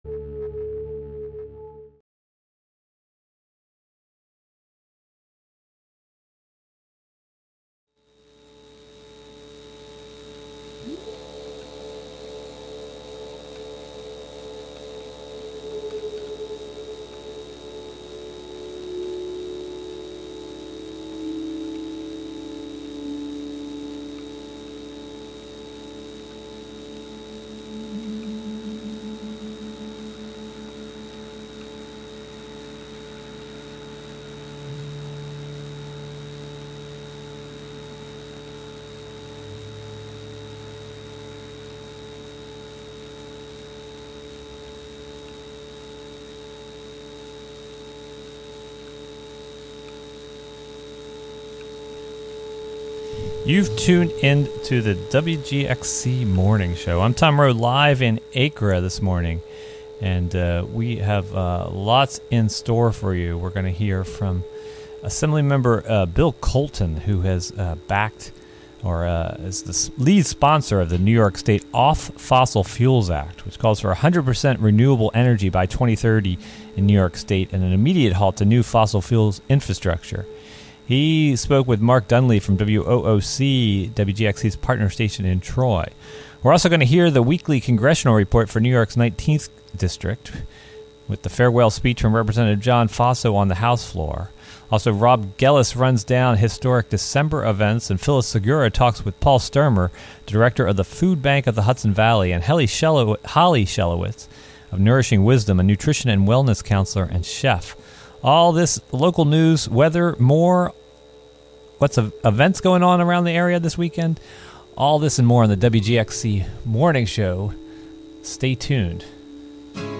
And hear the weekly Congressional Report for New York's 19th District, with the farewell speech from Rep. John Faso from the House floor.
The WGXC Morning Show is a radio magazine show featuring local news, interviews with community leaders and personalities, a rundown of public meetings, local and regional events, with weather updates, and more about and for the community.